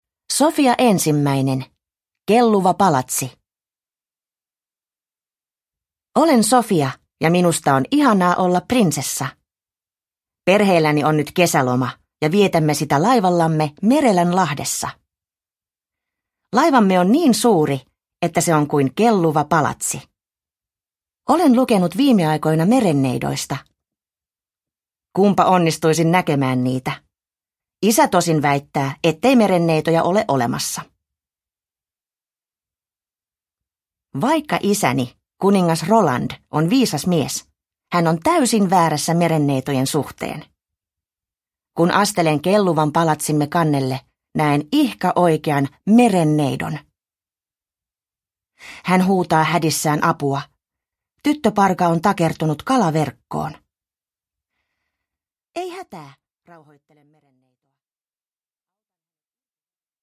Sofia ensimmäinen. Kelluva palatsi – Ljudbok – Laddas ner